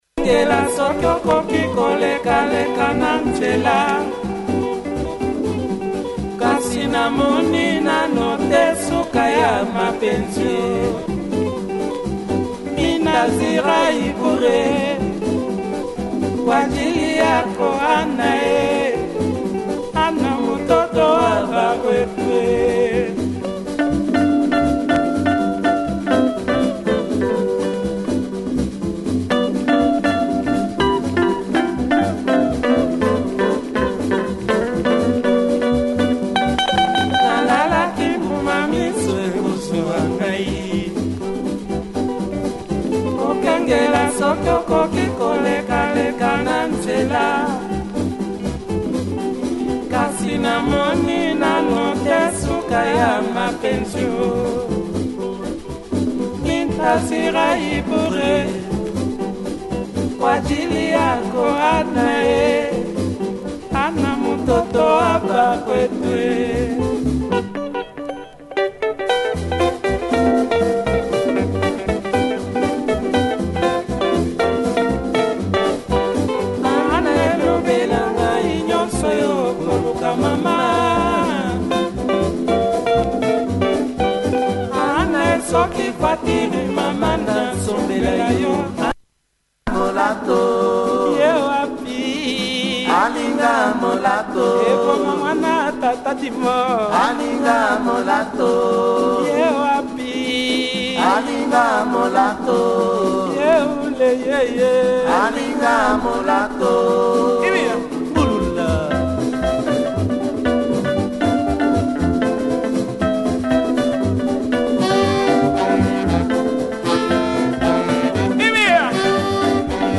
Very rare and good Congo in Kenya styled groover